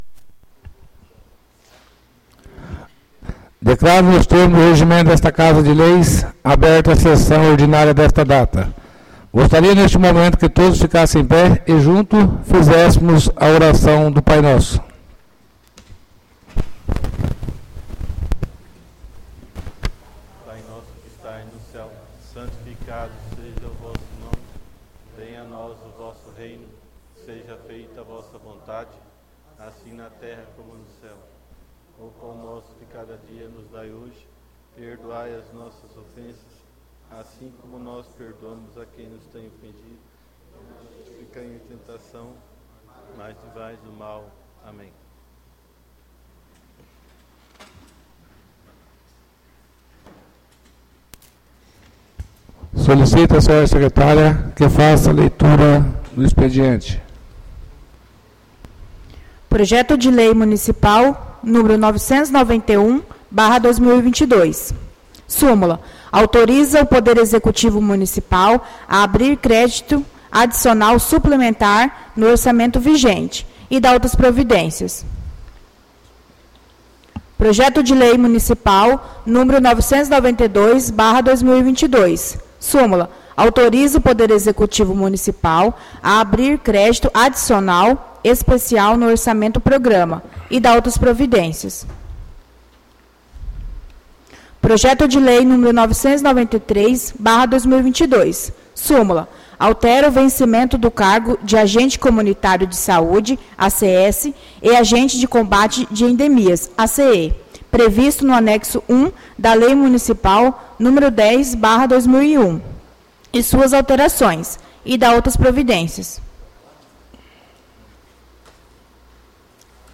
ÁUDIO SESSÃO 08-08-22 — CÂMARA MUNICIPAL DE NOVA SANTA HELENA - MT